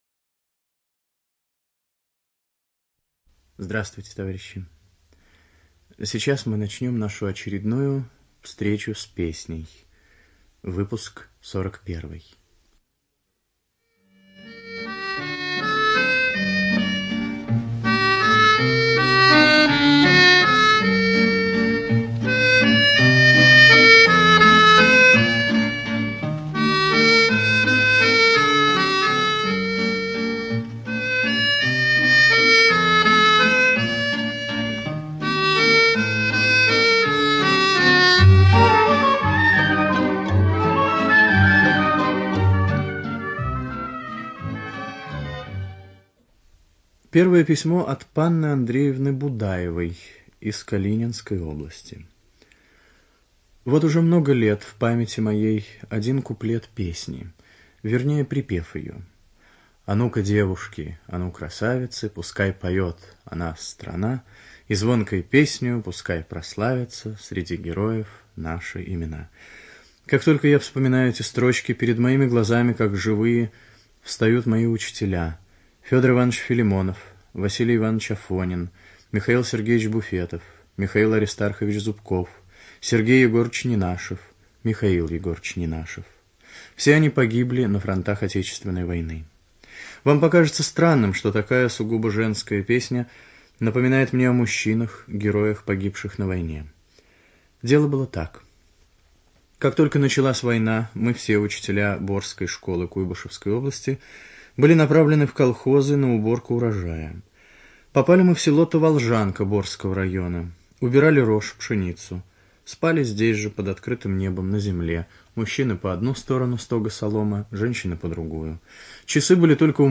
ЧитаетТатарский В.
ЖанрРадиопрограммы